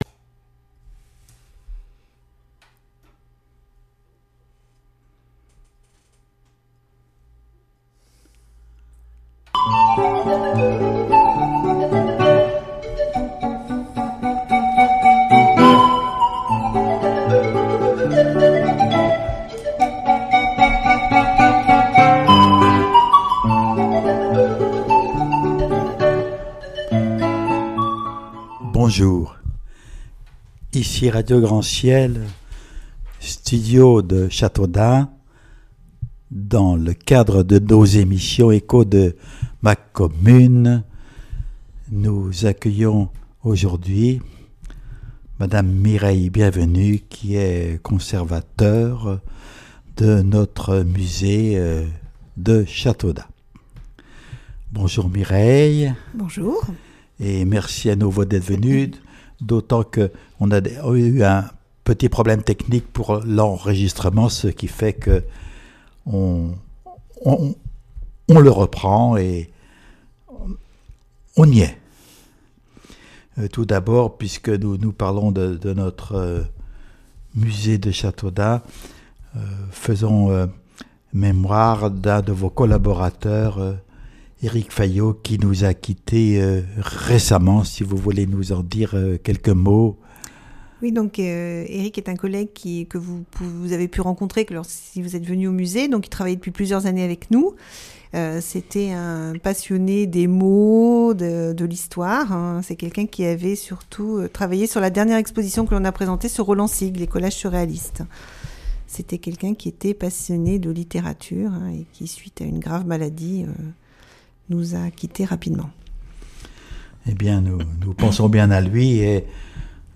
La commune de Luray Rediffusion d'une émission enregistrée avec Alain FILLON, maire de Luray